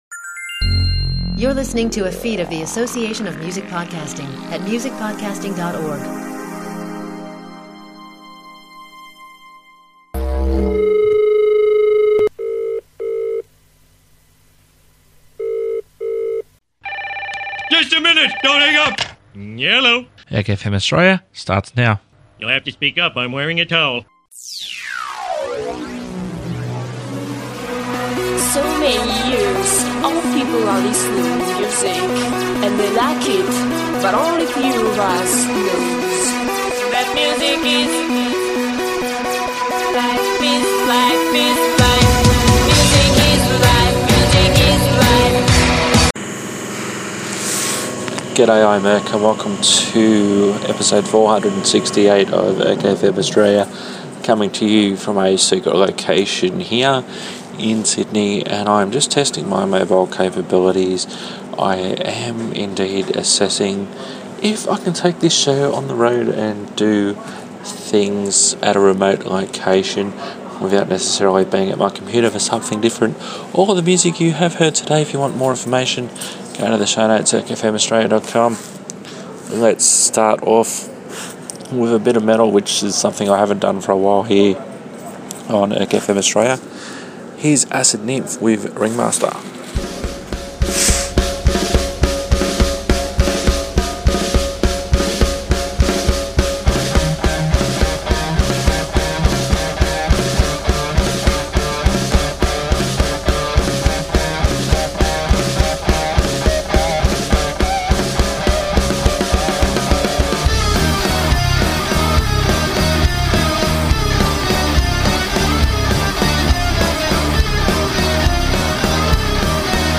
As a result, the show will sound different than usual.